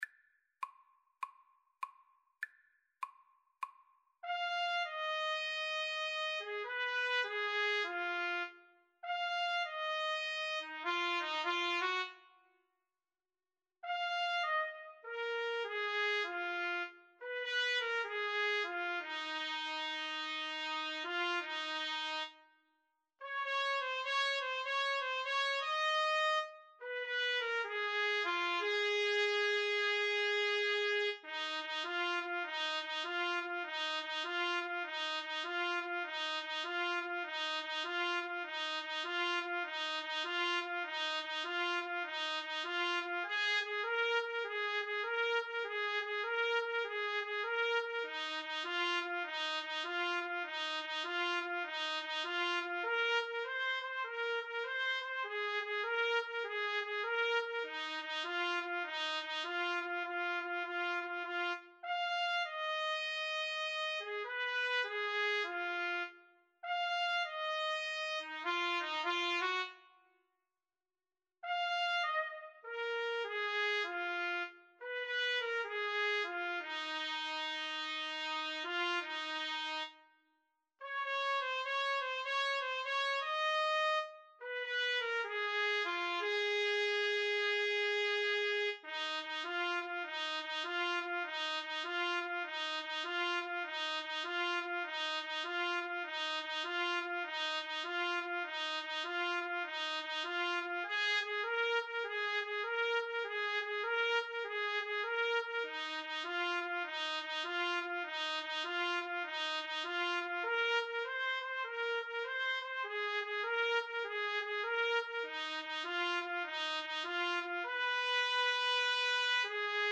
Trumpet Duet version
a popular American 12-bar blues song
Moderato